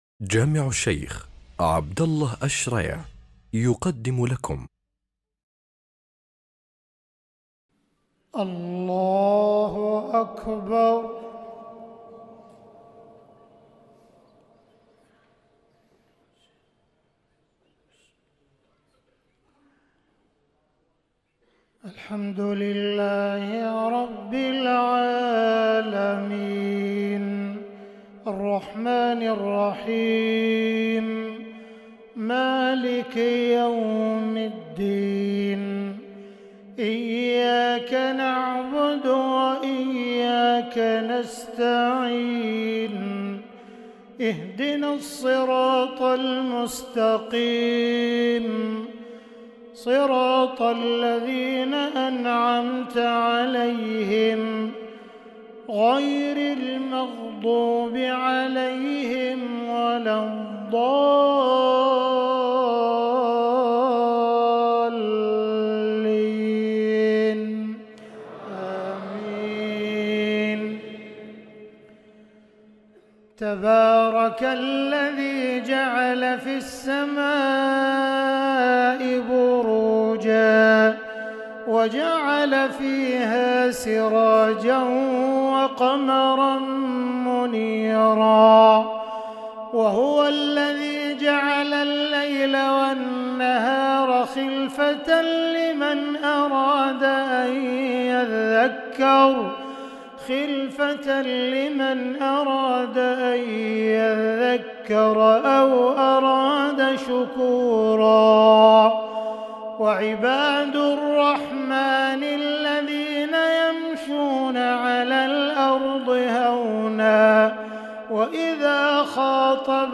صلاة العشاء 19رجب 1444هـ جامع عبدالله الشريع تلاوة لخواتيم سورة الفرقان 61-77